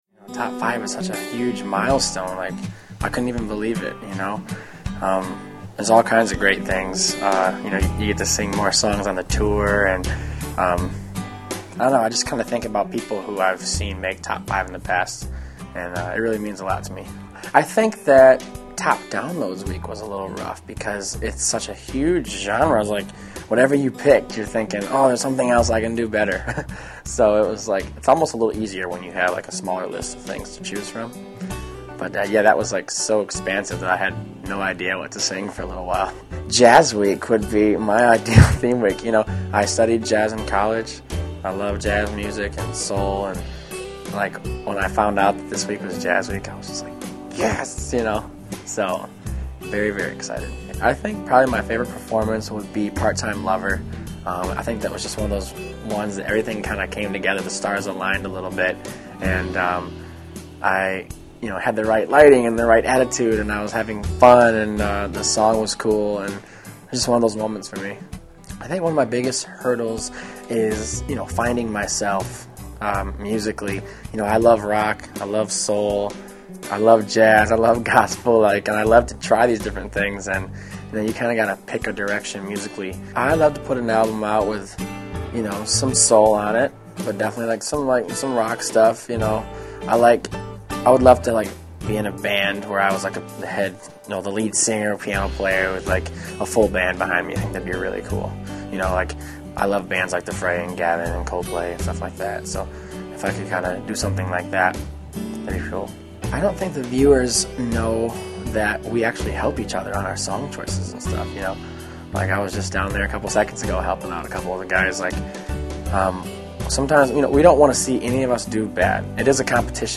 Matt Giraud interview
Category: Television   Right: Personal